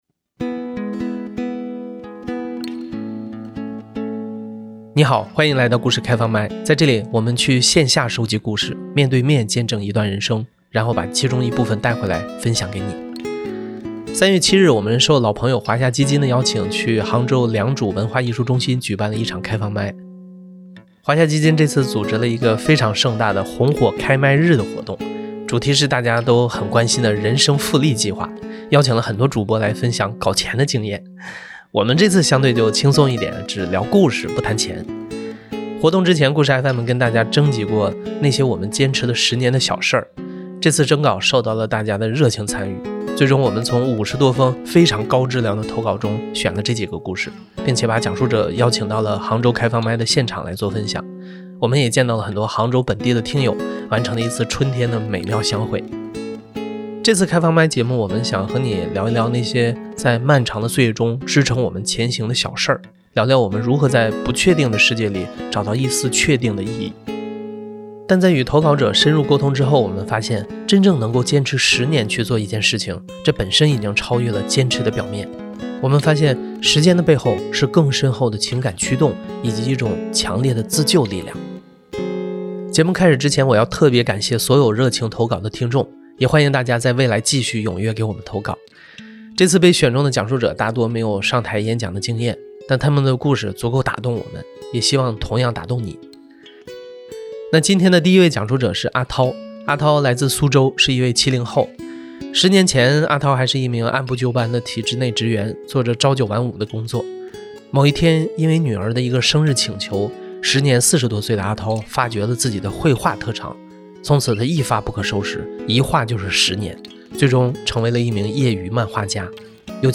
3 月 7 日，我们去杭州良渚文化艺术中心举办了一场开放麦。
这次被选中的讲述者，大多没有舞台演讲经验，但他们的故事足够打动我们，也希望同样打动你。
故事FM 是一档亲历者自述的声音节目。